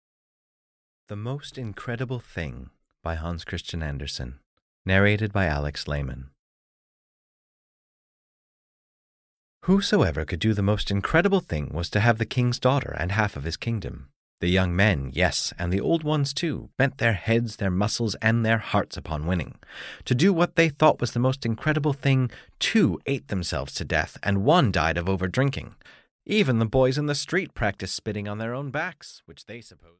The Most Incredible Thing (EN) audiokniha
Ukázka z knihy